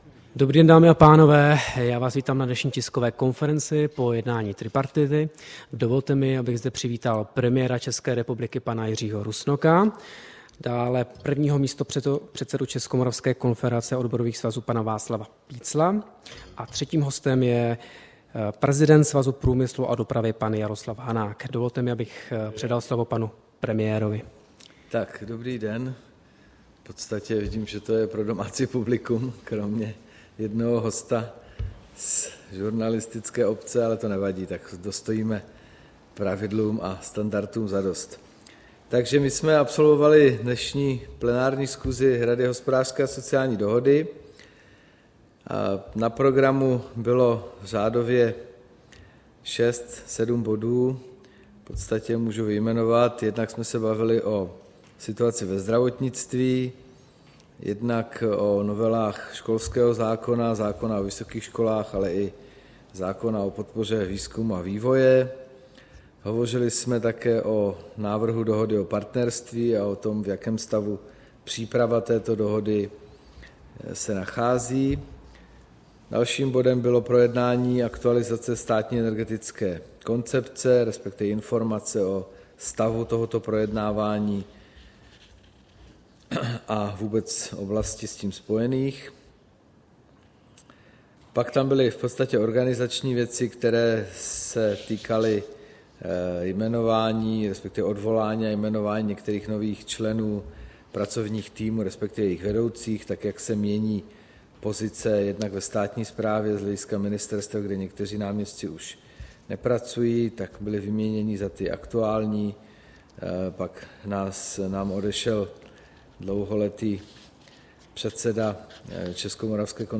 Tisková konference po jednání tripartity, 3. prosince 2013